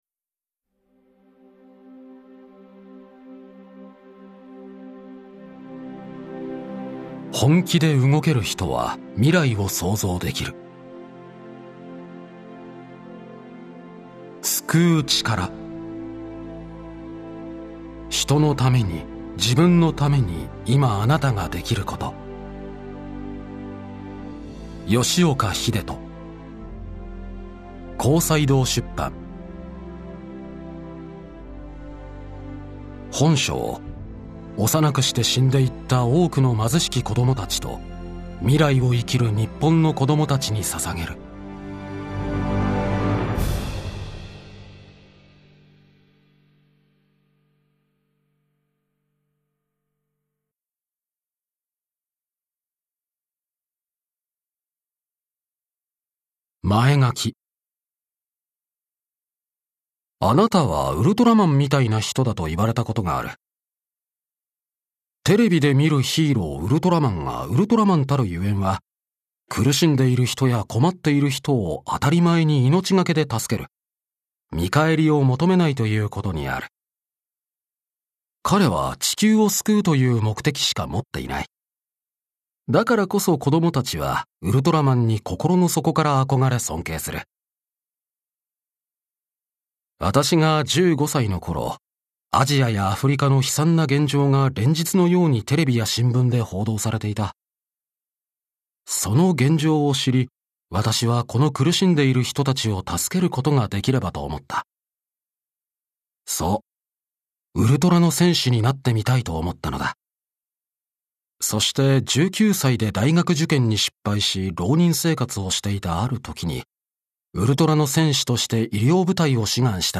[オーディオブック] 救う力 人のために、自分のために、いまあなたができること